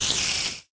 minecraft / sounds / mob / spider